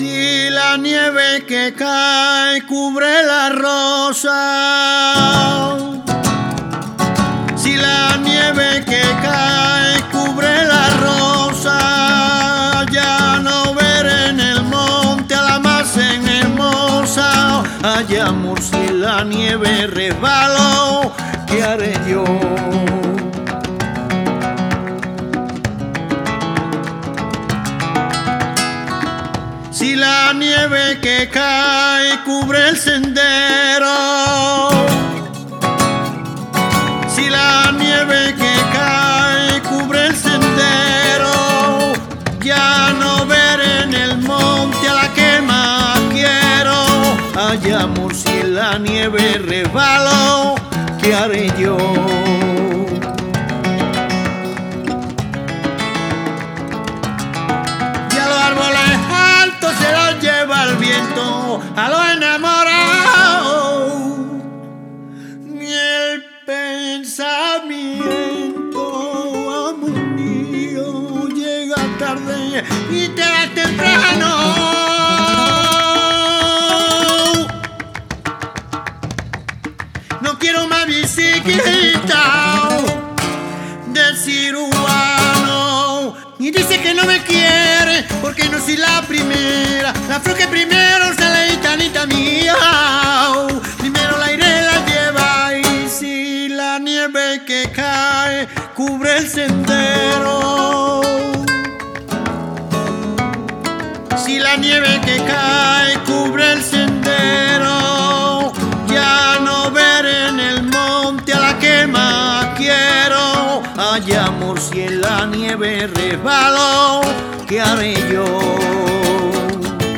percussions